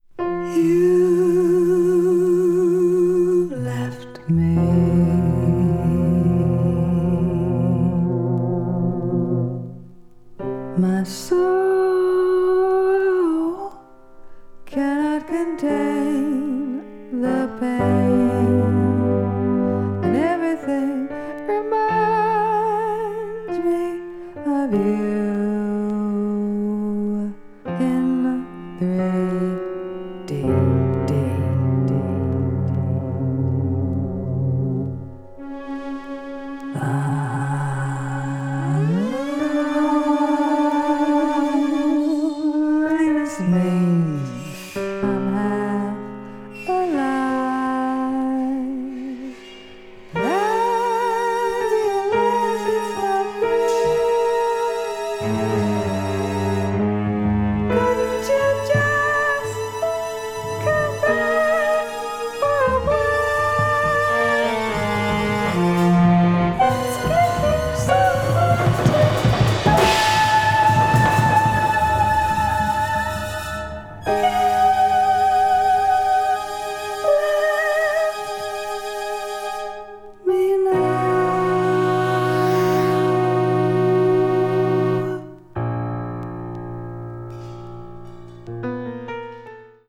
avant-garde   avant-jazz   contemporary jazz   jazz vocal